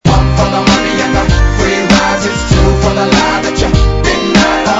• Hip-Hop Ringtones